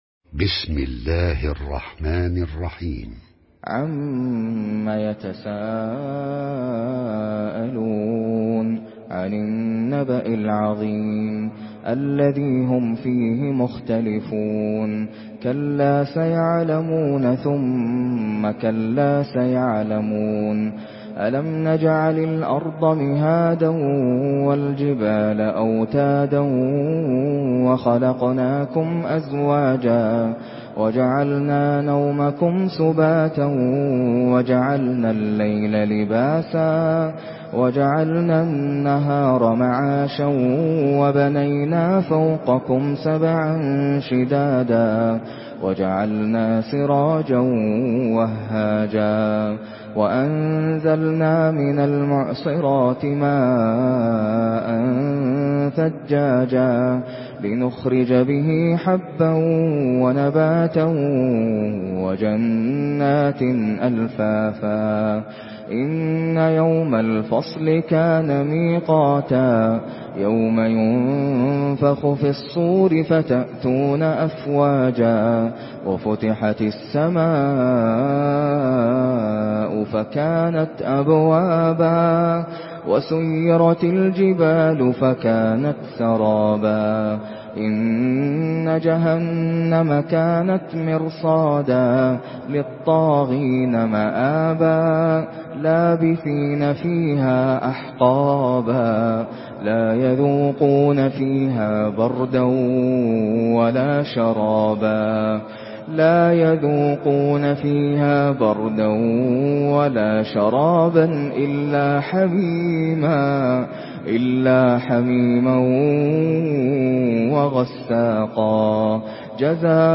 Surah النبأ MP3 in the Voice of ناصر القطامي in حفص Narration
مرتل